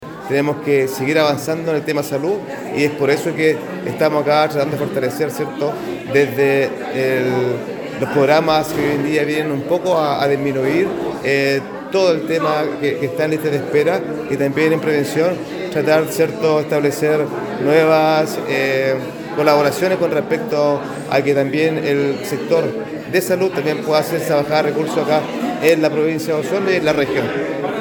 En tanto, el presidente de la Comisión Provincial Osorno del Consejo Regional de Los Lagos, Francisco Paredes, señaló que se debe continuar  avanzando en materia de salud, fortaleciendo los programas que hoy en día ayudan a reducir las listas de espera y a mejorar la prevención.